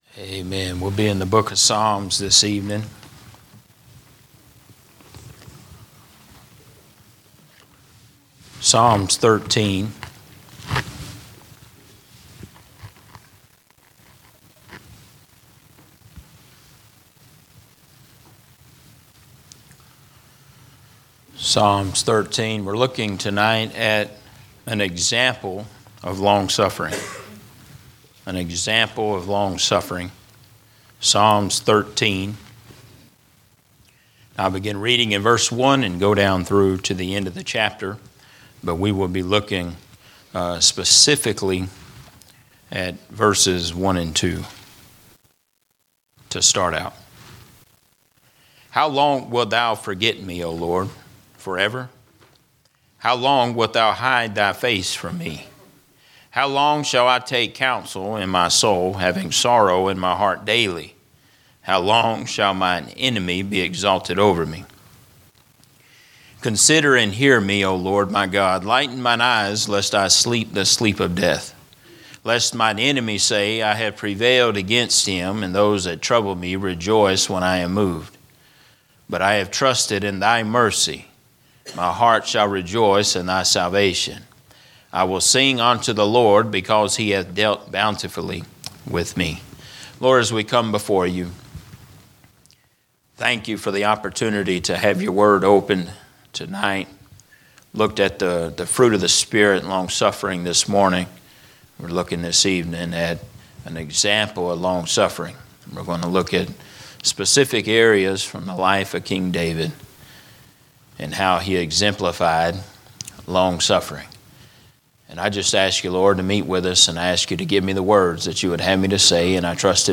This sermon explores the life of David—a man who transitioned from the heights of the palace to the depths of the cave, yet remained anchored in God’s sovereignty.